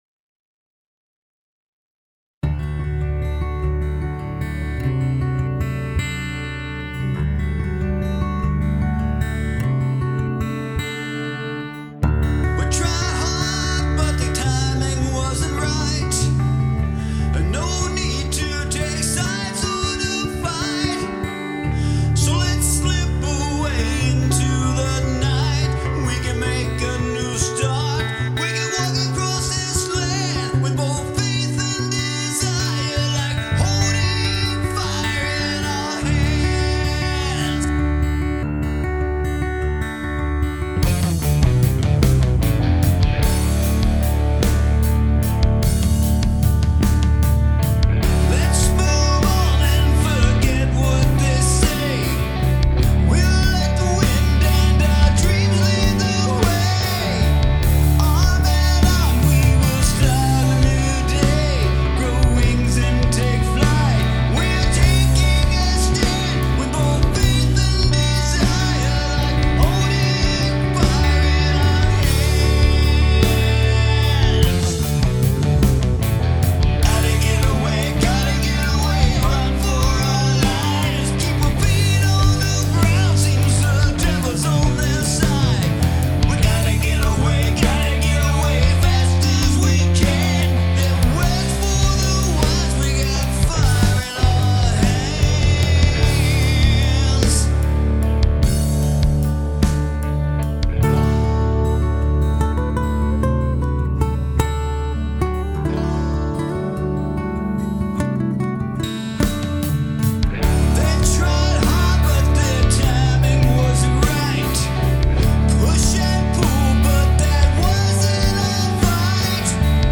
Like Holding Fire In Our Hands   Genre: Roots-Rock/Alt-Rock   Rating
I may have mentioned it before, but it’s worth repeating: it’s hard to make looped/virtual drums—this case, Superior Drummer 3—sound real.
Then there are the synth pads, courtesy of a UVI Falcon Synth, that fill out the sonic landscape of the song without getting in the way. The background vocals are also well done; their placement helps to support the song further.
“Like Holding Fire In Our Hands” is an excellent recording of a well-written song.